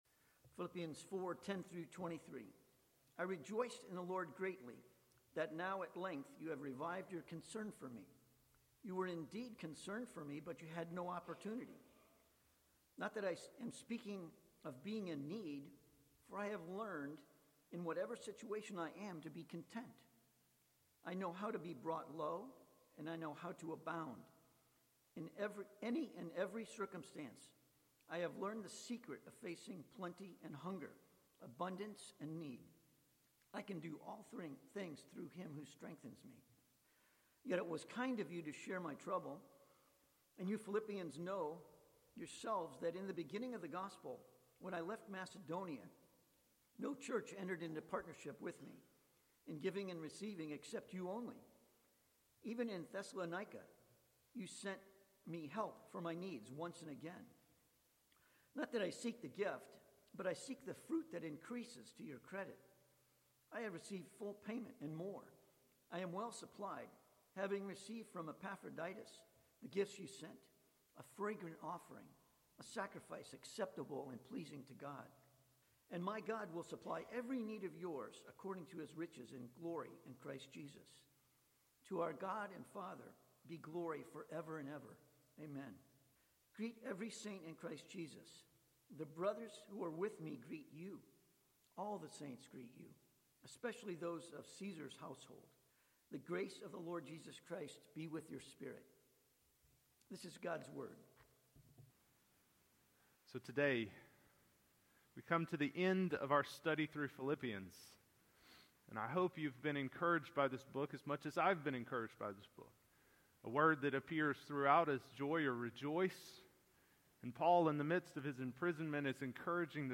Passage: Philippians 4:10-23 Sermon